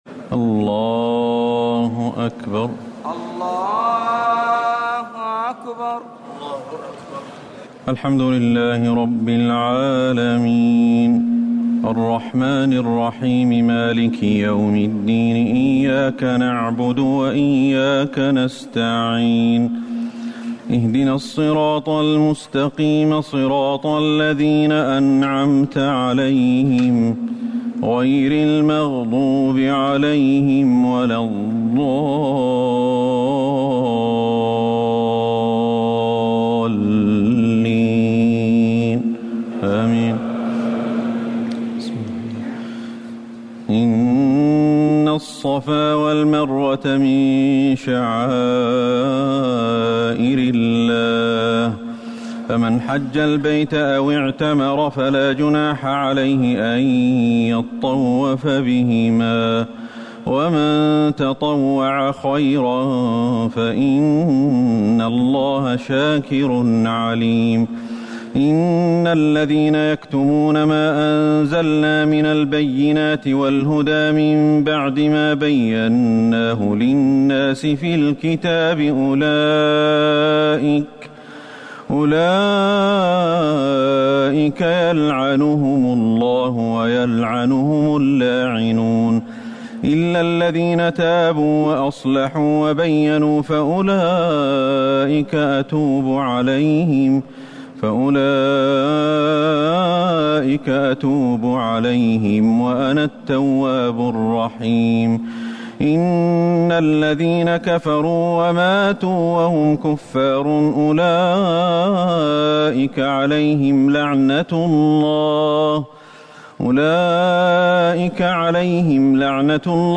تراويح الليلة الثانية رمضان 1439هـ من سورة البقرة (158-232) Taraweeh 2 st night Ramadan 1439H from Surah Al-Baqara > تراويح الحرم النبوي عام 1439 🕌 > التراويح - تلاوات الحرمين